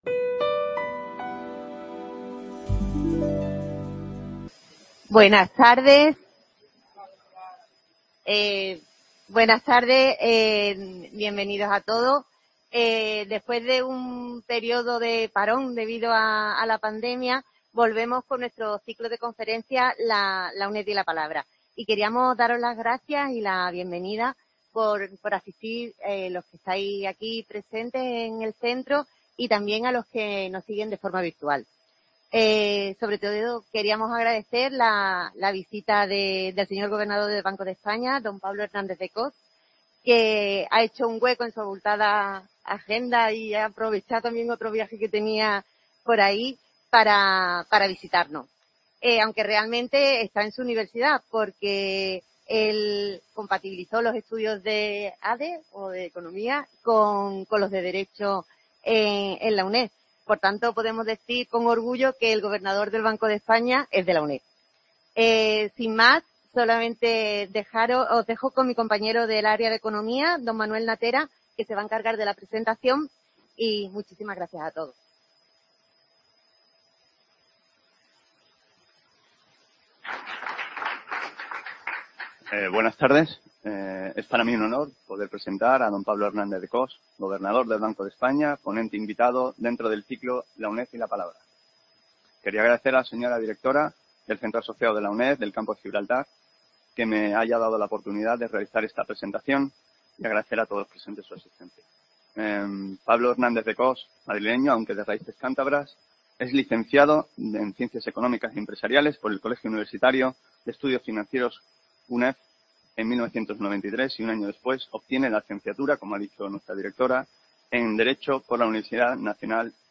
Conferencia del Gobernador del Banco de España
CA Campo De Gibraltar (Algeciras)